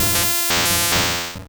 Cri d'Élektek dans Pokémon Rouge et Bleu.